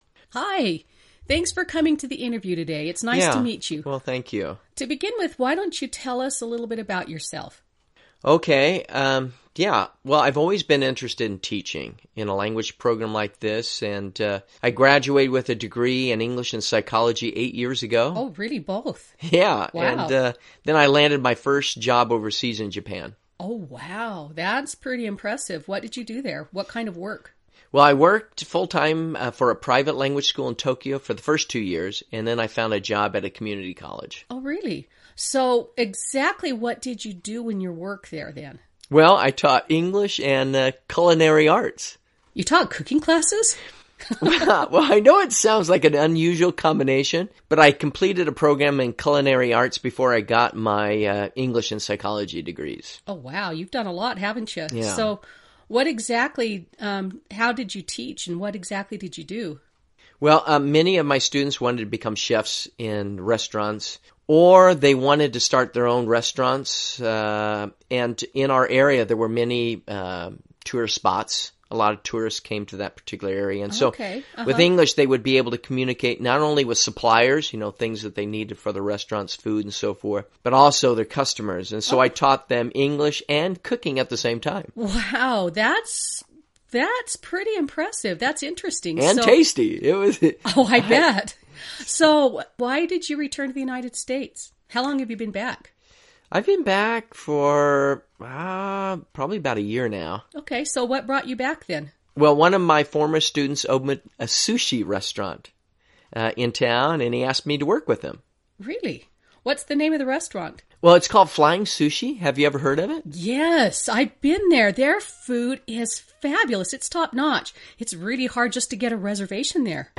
Job-Interview.mp3